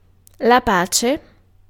Ääntäminen
US : IPA : [ˈkwaɪ.ət]